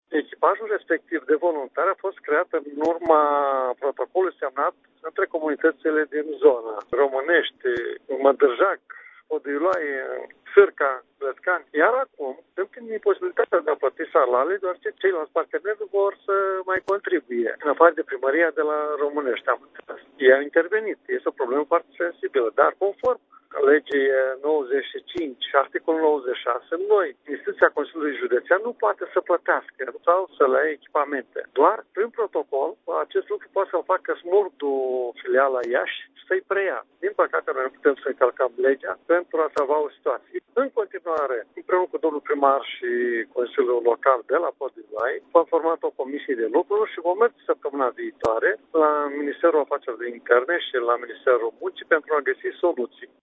S-a decis ca vineri, 9 iunie să aibă loc o altă întâlnire, protestatarii de la Fortus solicitând prezența prim-ministrului Sorin Grindeanu, a spus Marian Șerbescu: